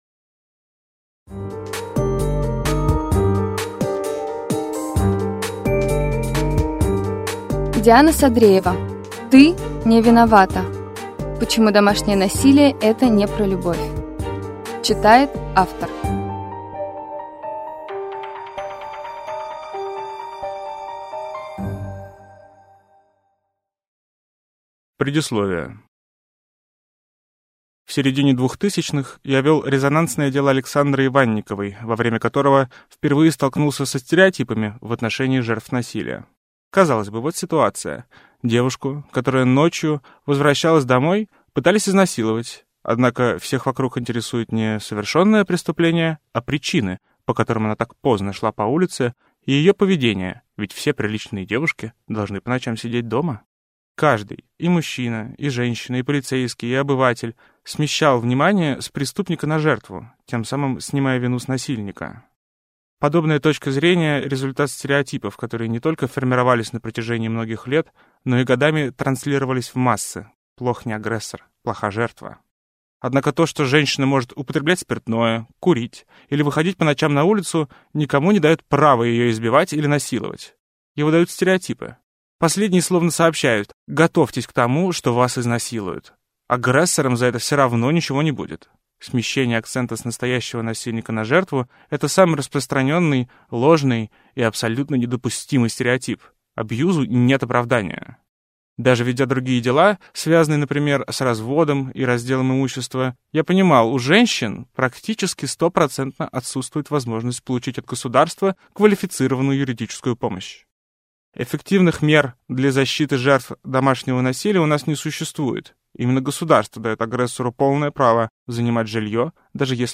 Аудиокнига Ты не виновата | Библиотека аудиокниг